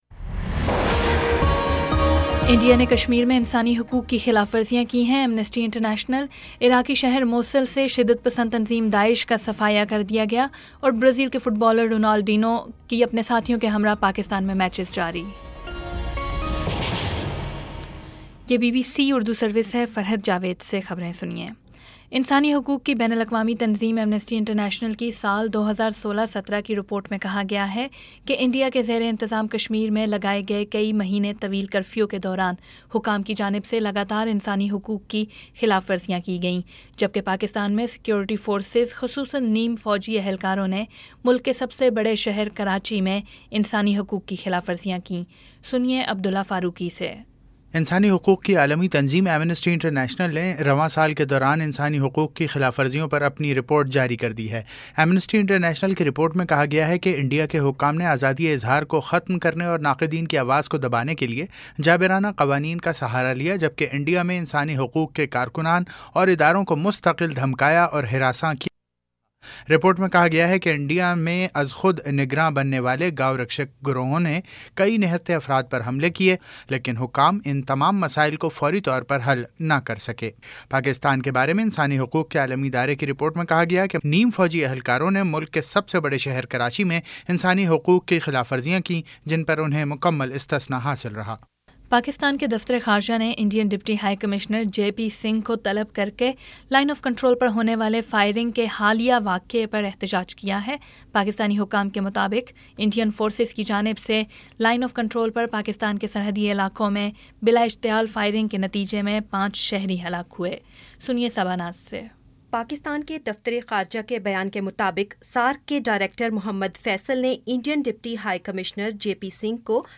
جولائی 09 : شام سات بجے کا نیوز بُلیٹن